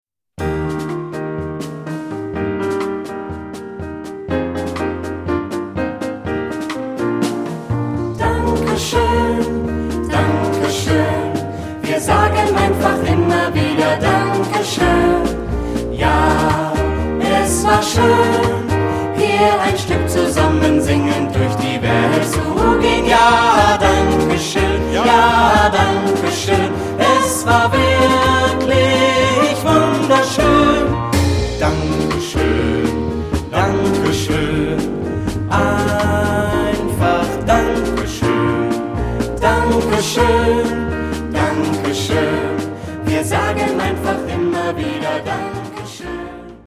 Ad libitum (3 Ad libitum Stimmen).
Kanon. Choraljazz.
Charakter des Stückes: jazzy ; rhythmisch ; leicht
Tonart(en): Es-Dur